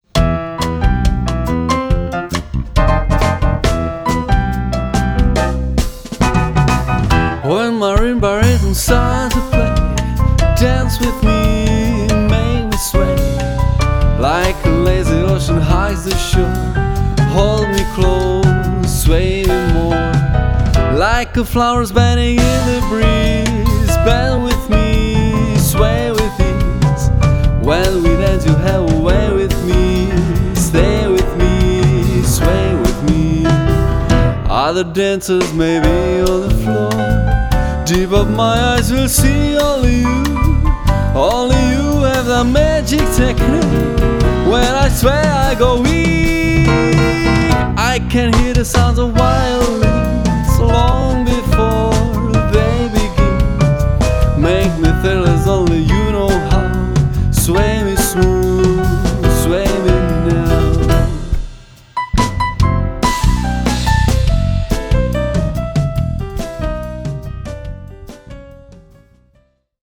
bicí